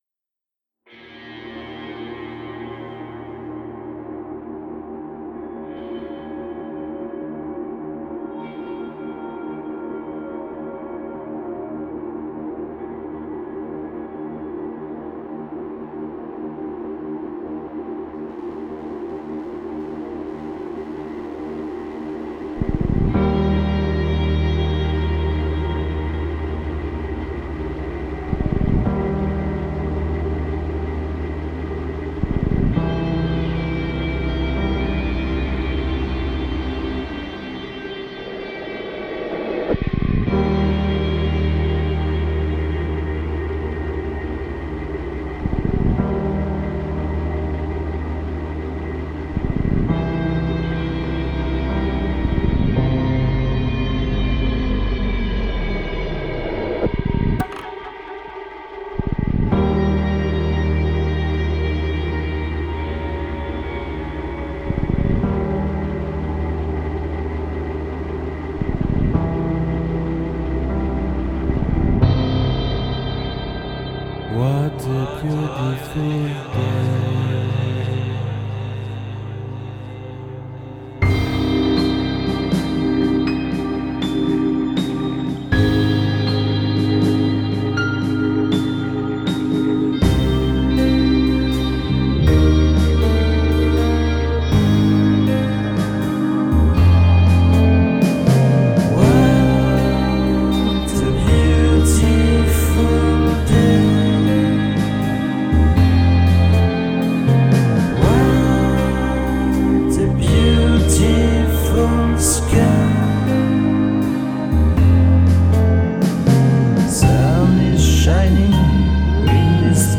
voix, guitare, clavier, basse, programmation
harmonica
remix